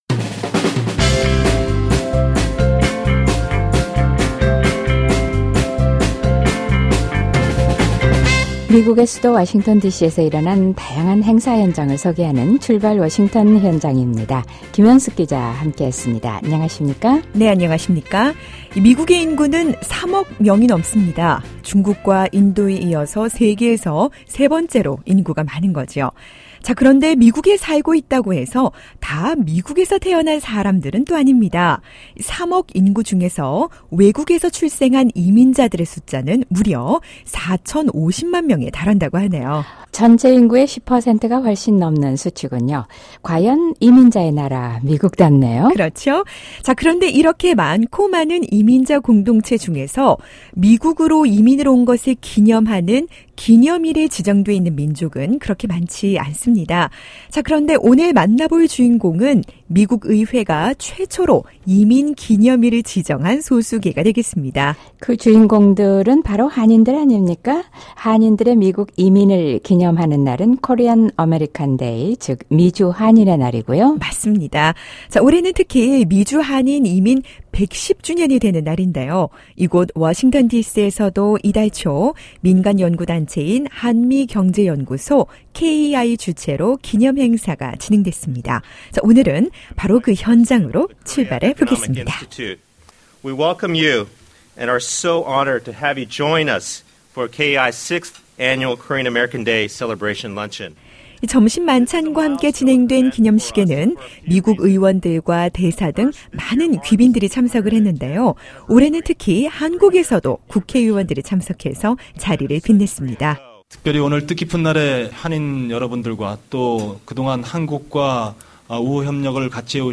미주 한인 이민 110주년을 맞아 워싱턴 디씨에서는 미주 한인의 날 축하 행사가 열렸습니다. 올해는 김용 세계은행 총재 등 지역 사회를 위해 헌신하고 봉사한 한인들이 자랑스런 한인으로 선정돼 자신들의 경험담을 나눴는데요.